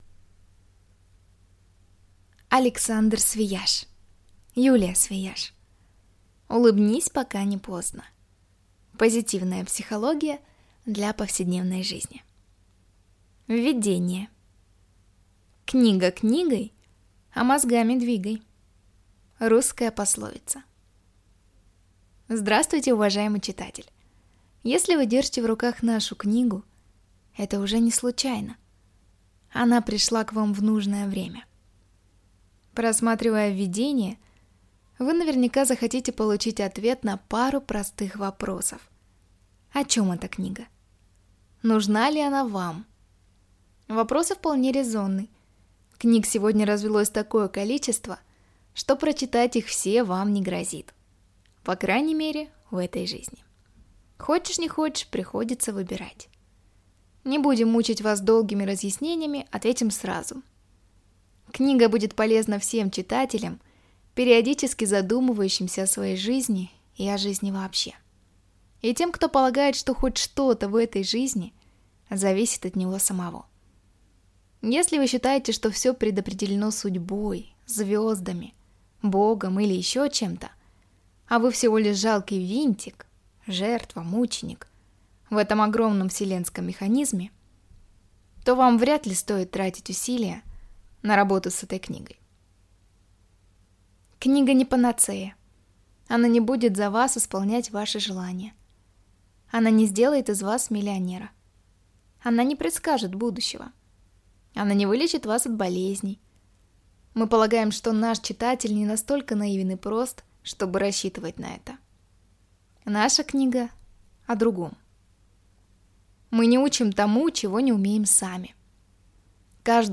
Аудиокнига Улыбнись, пока не поздно. Позитивная психология для повседневной жизни | Библиотека аудиокниг